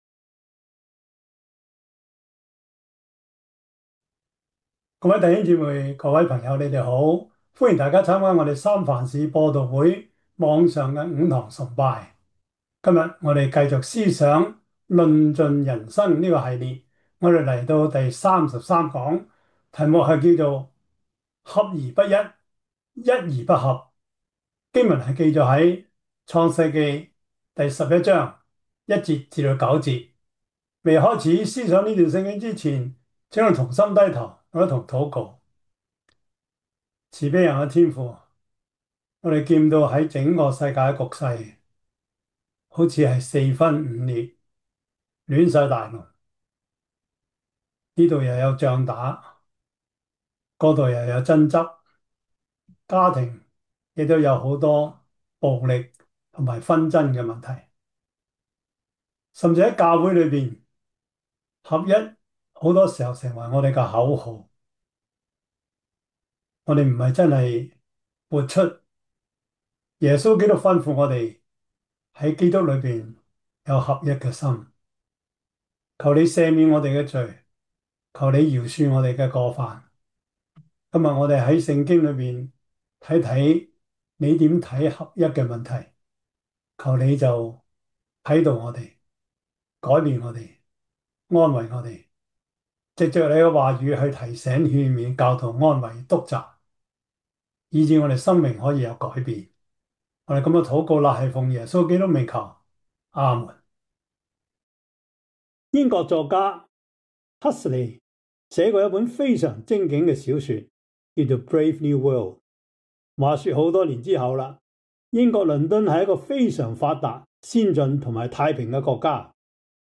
11:1-9 Service Type: 主日崇拜 創世記 10:6-12 Chinese Union Version